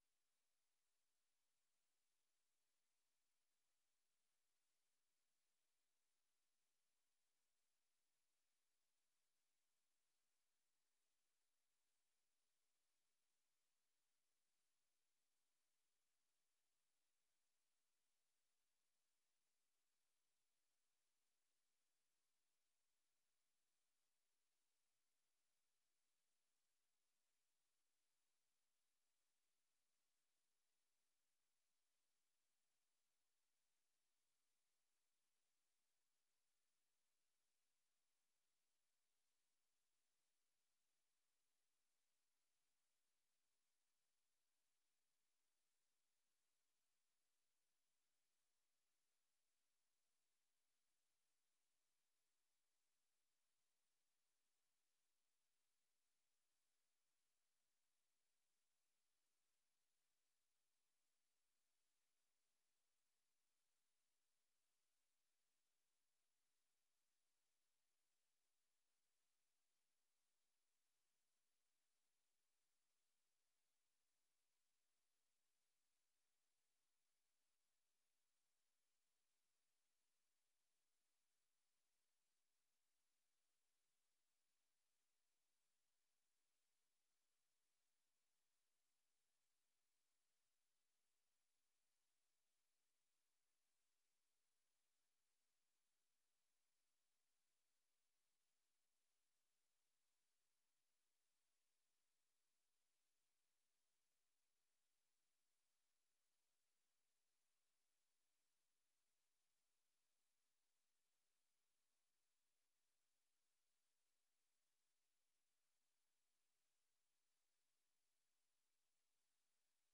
Gemeenteraad 24 september 2024 20:00:00, Gemeente West Betuwe
Download de volledige audio van deze vergadering